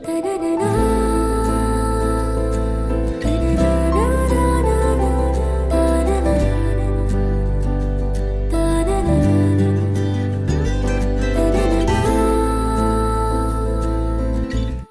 best flute ringtone download | love song ringtone
melody ringtone romantic ringtone